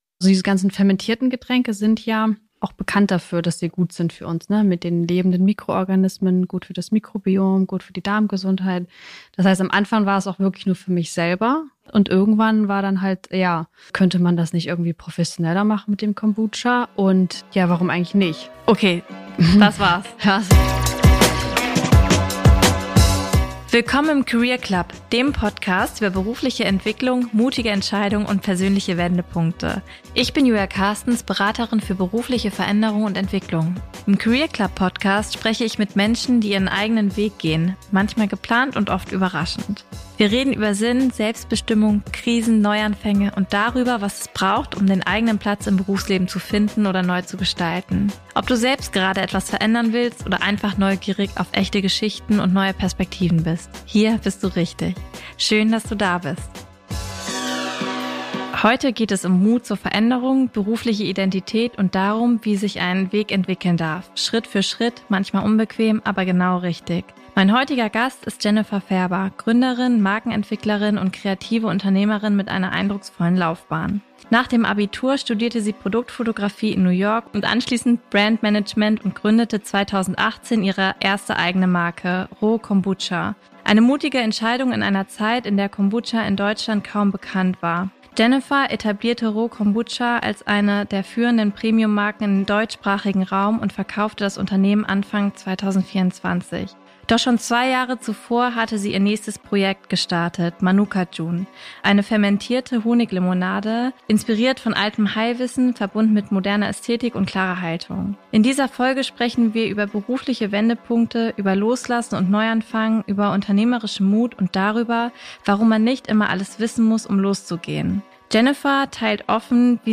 Ein Gespräch über Selbstführung, Scheitern, Stärke und zweiten Anlauf, das zeigt: Gründen ist keine Gerade.